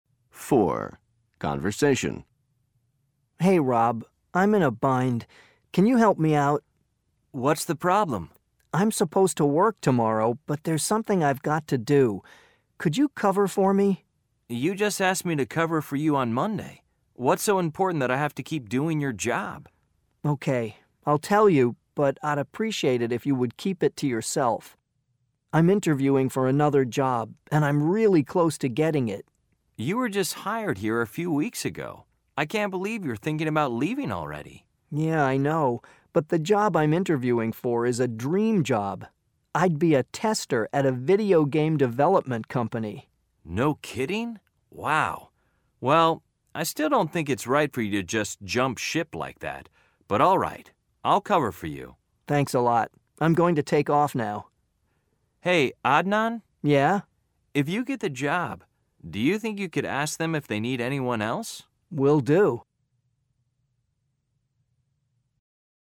مقطع للوحده الخامسهMG22_U5_L4_Conversation_Track